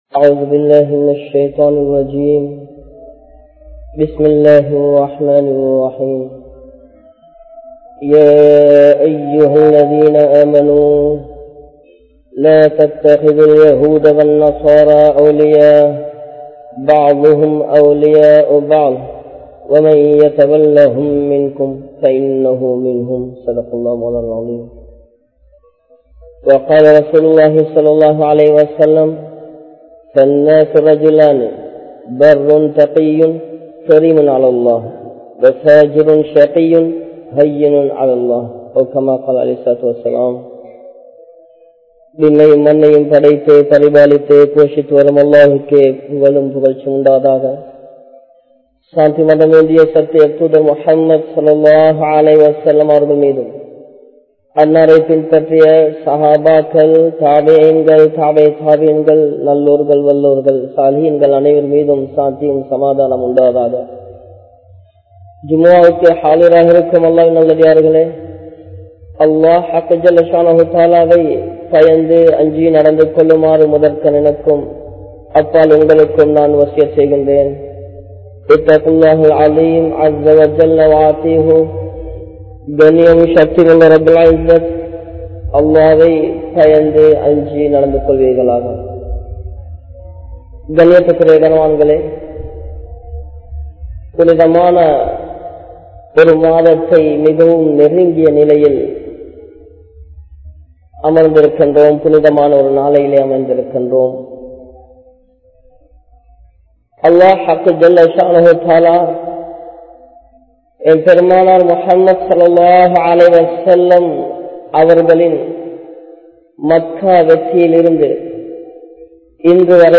Maarkam Sollum Poruppai Ulamakkalukku Vittu Vidungal (மார்க்கம் சொல்லும் பொறுப்பை உலமாக்களுக்கு விட்டு விடுங்கள்) | Audio Bayans | All Ceylon Muslim Youth Community | Addalaichenai
Muhiyaddeen Grand Jumua Masjith